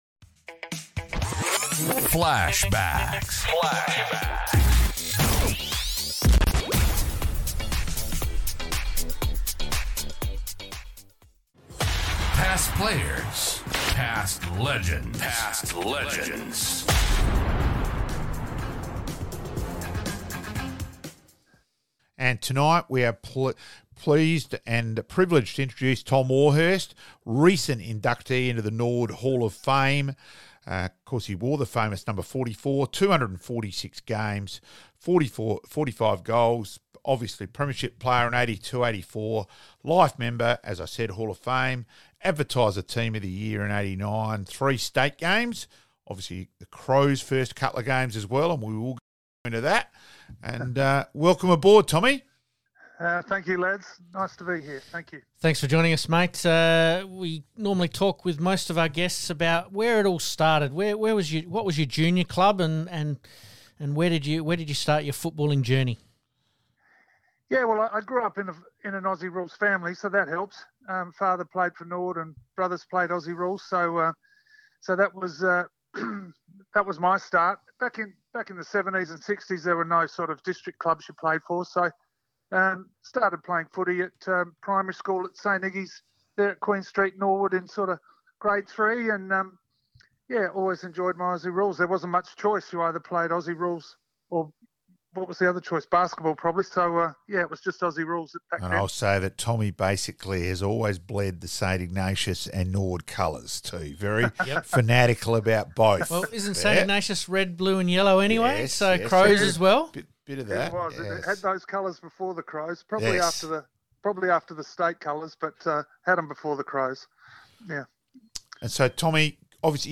FLASHBACKS - Re-Live some of our Interviews (only) with some of our special guests